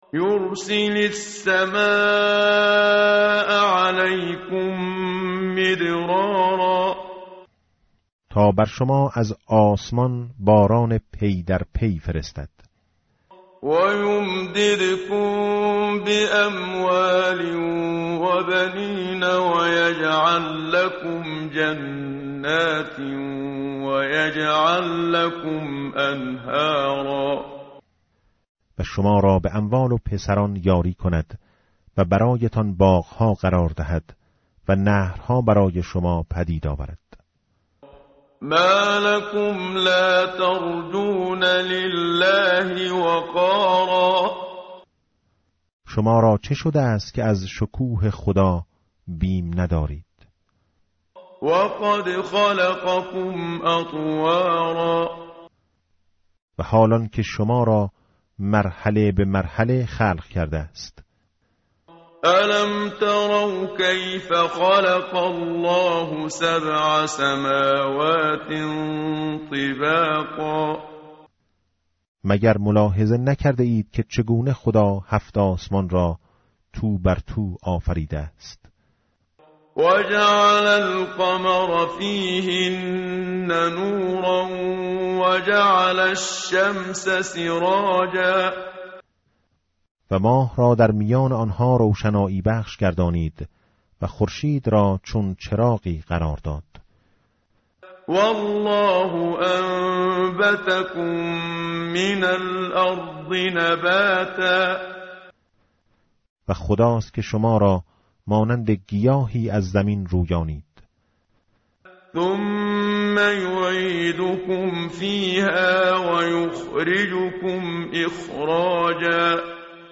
tartil_menshavi va tarjome_Page_571.mp3